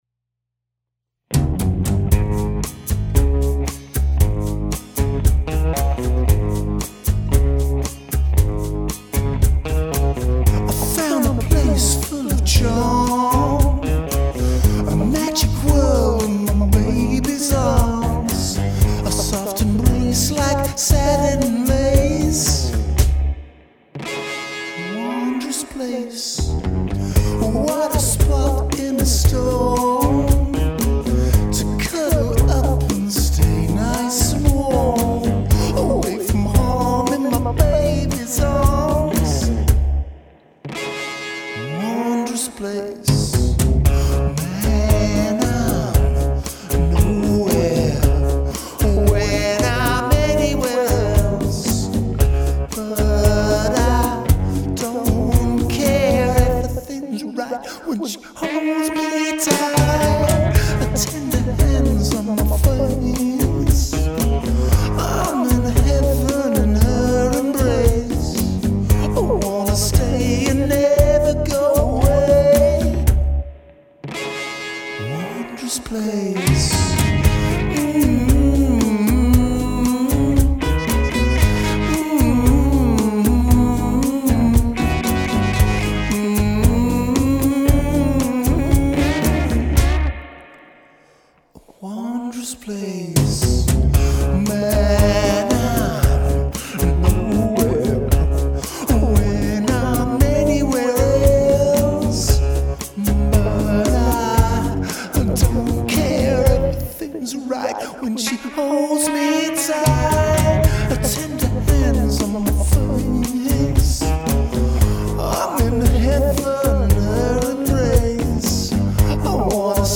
drums
sax
Double Bass
trumpet